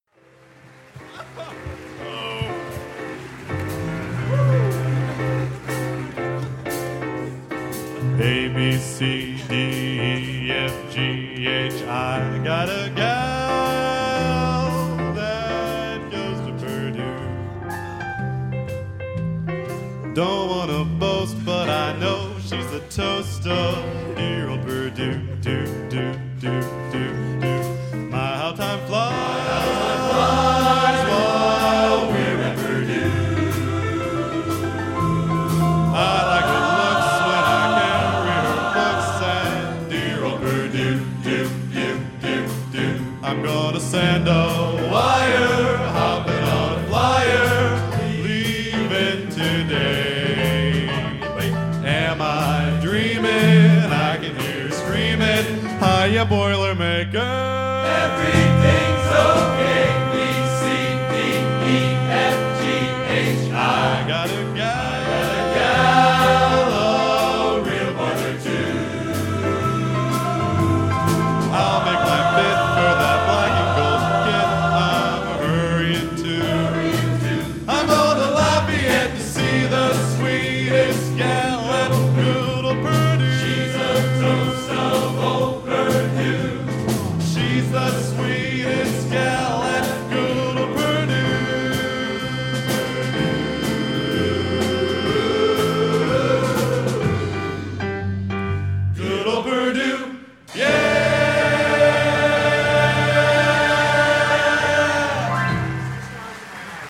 Location: Rossville High School, Rossville, Indiana
Genre: Humor/Parody Popular / Standards | Type: Solo